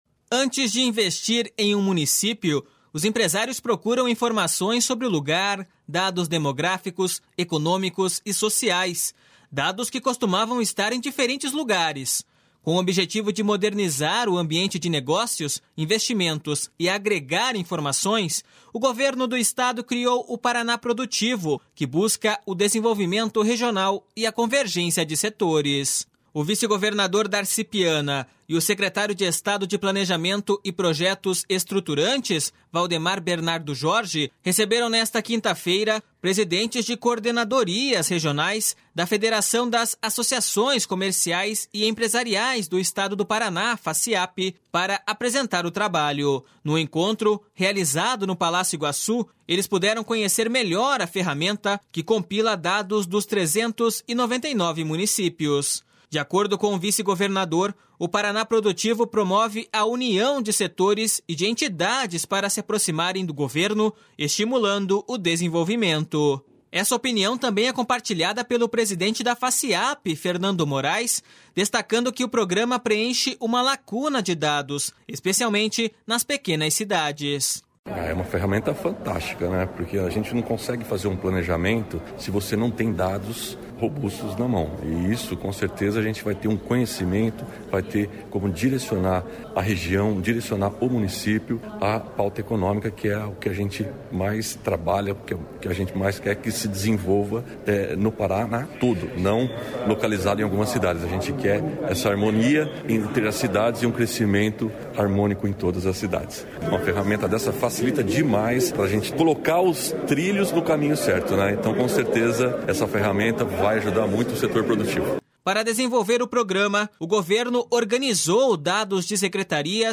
Juntas, elas detêm 25% do PIB e concentram 30% da população.// SONORA VALDEMAR BERNARDO JORGE.//